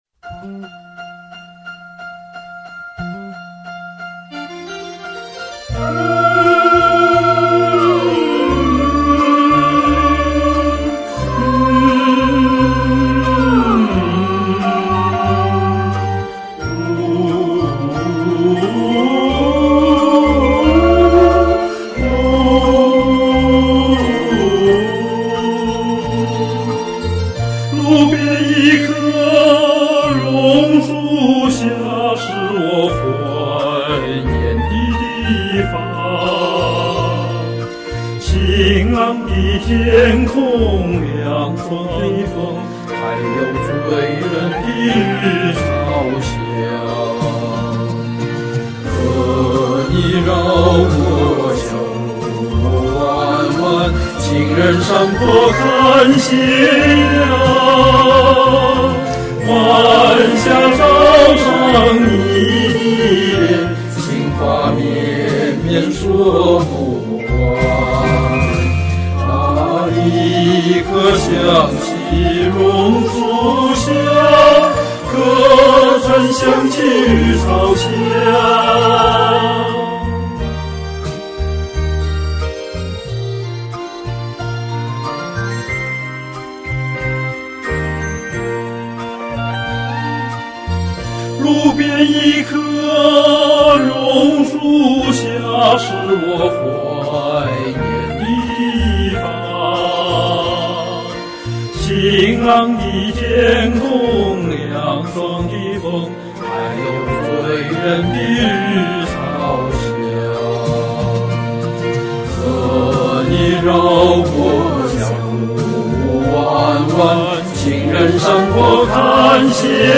抒情，温存，唱暖了榕树，唱动了人心，热烈鼓掌！
亲切的旋律， 优美和谐的歌声！